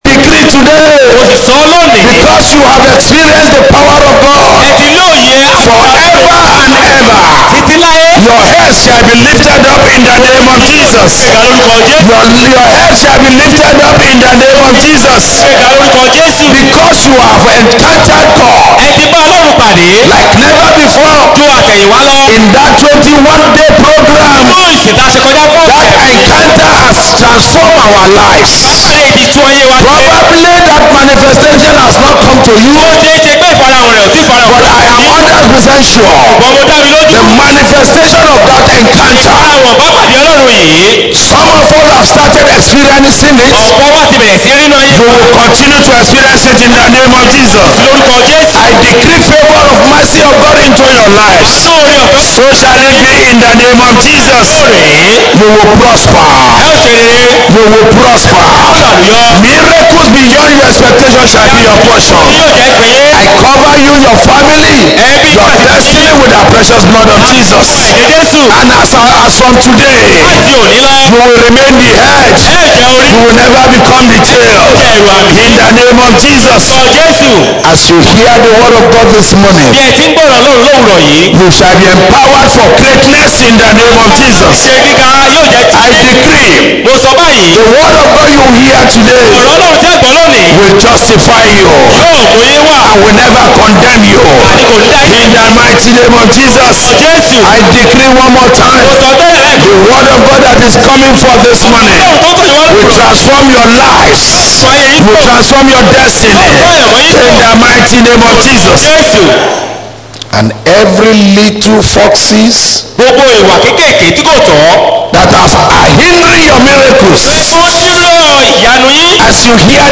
Posted in Sunday Service